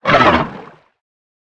Sfx_creature_pinnacarid_ridehurt_01.ogg